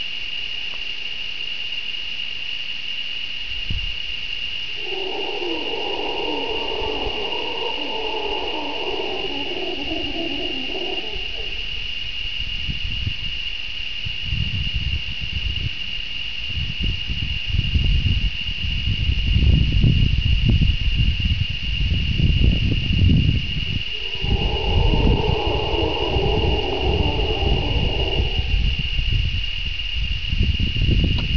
In the jungle the sound of the crickets can be maddening. And then suddenly you hear a howler monkey.
Howler monkey (WAV sound, 338 KB)